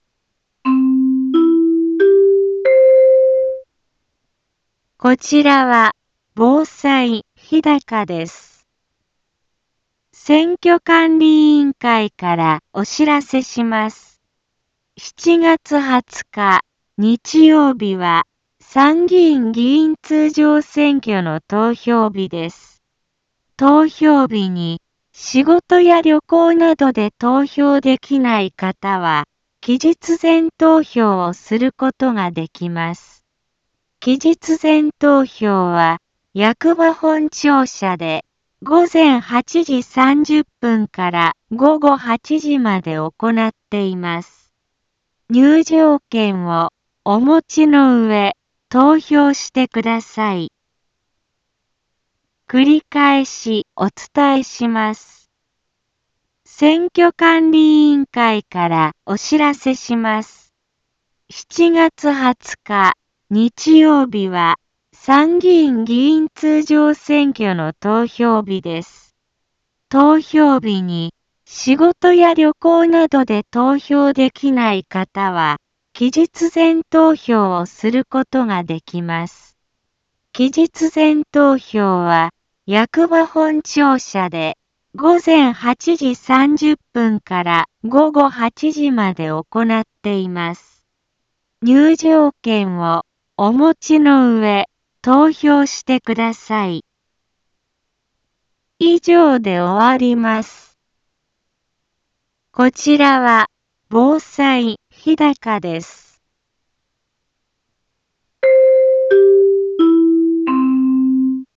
Back Home 一般放送情報 音声放送 再生 一般放送情報 登録日時：2025-07-04 10:03:56 タイトル：参議院議員通常選挙投票棄権防止の呼びかけ インフォメーション： こちらは、防災日高です。